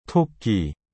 Qual é a pronúncia correta de “토끼”?
A pronúncia de 토끼 (tokki) segue uma regra básica: a primeira sílaba,  (to), tem um som curto e claro. Já a segunda sílaba, (kki), é pronunciada com um som mais forte por conta da consoante dupla (ㄲ).